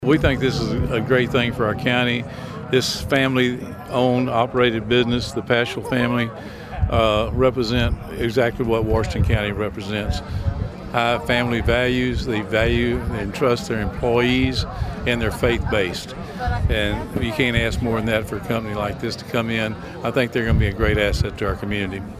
Washington County Judge John Durrenberger said he believes CRA will be a great fit for Washington County.